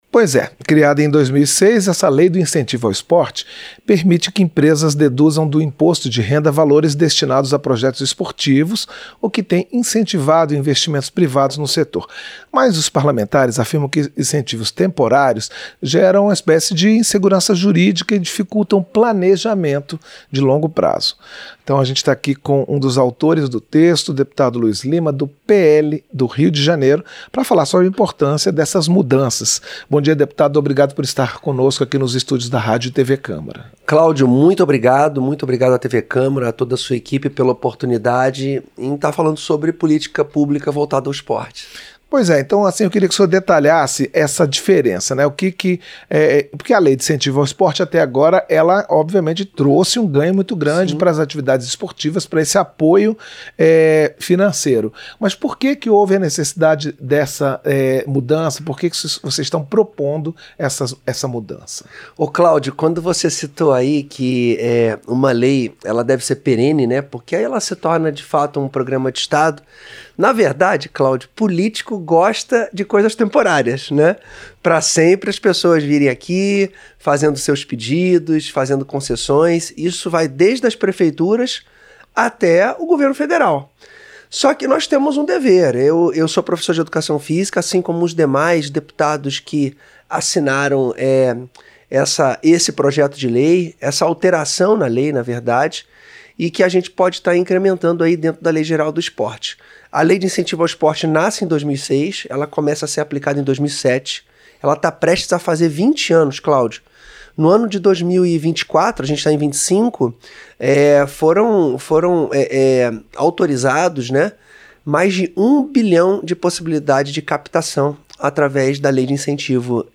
Entrevista - Dep. Luiz Lima (PL-RJ)